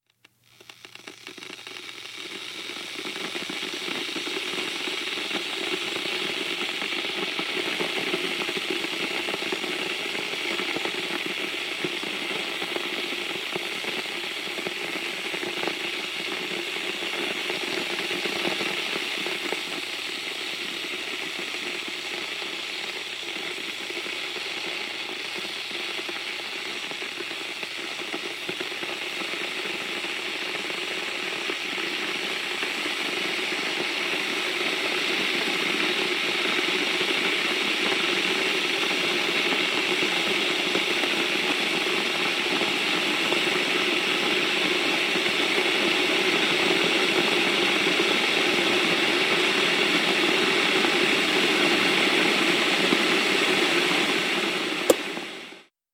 Звуки электрического чайника
На этой странице собраны звуки электрического чайника: от включения до характерного щелчка при завершении кипячения.
Звуки работы электрического чайника Электрический чайник и звуки кипения Чайник электрический со звуками